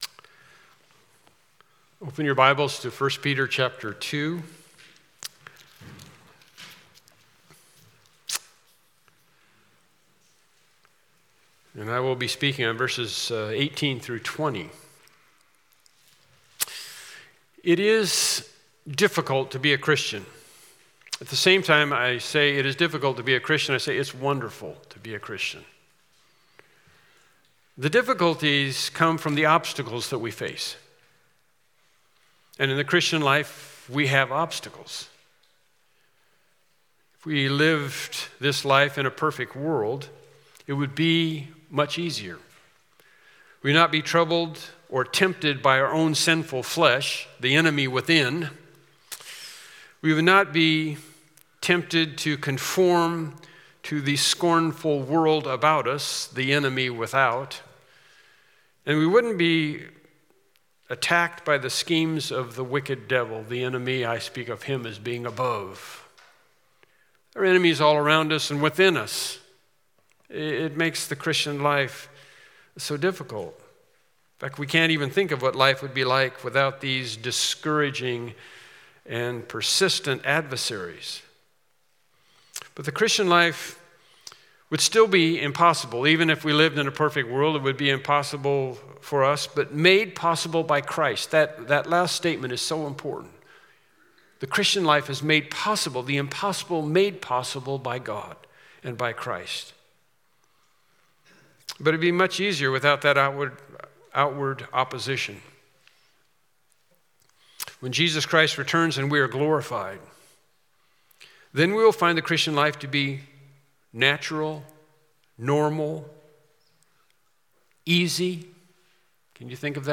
1 Peter 2:18-20 Service Type: Morning Worship Service Topics